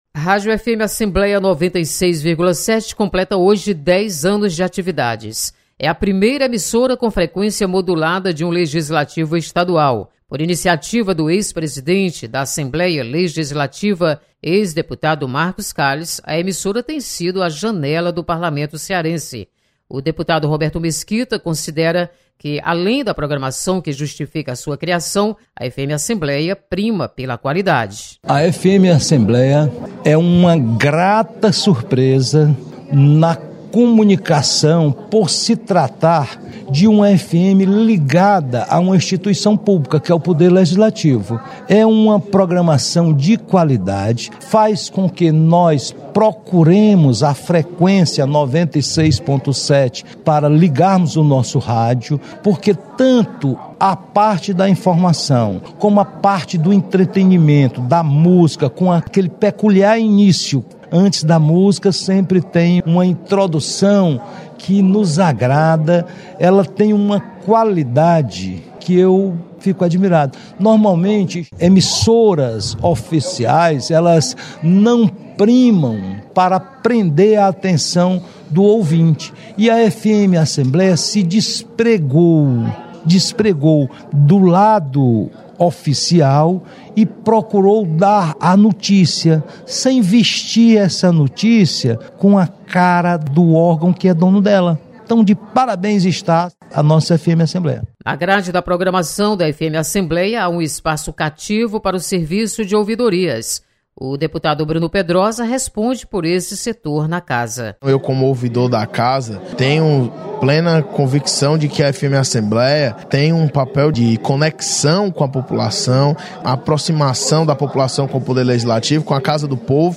Deputados destacam 10 anos de atividades da Rádio FM Assembleia. Repórter